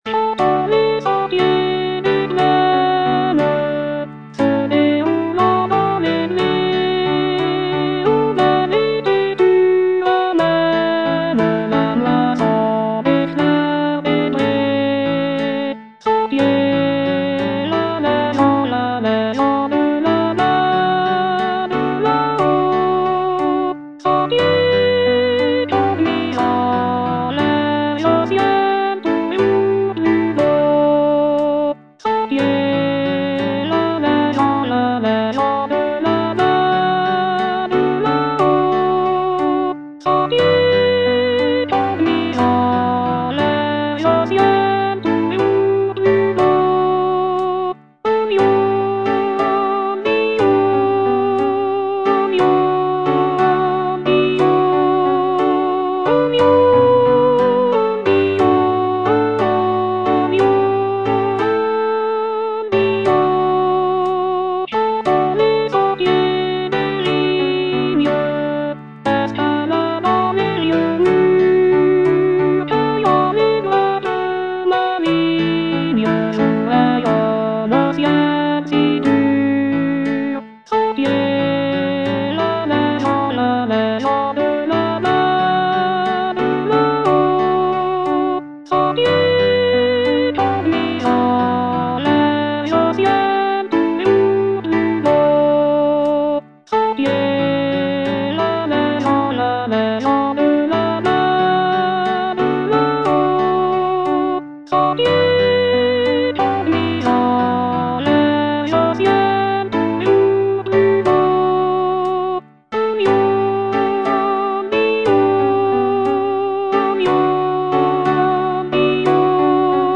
Alto (Voice with metronome)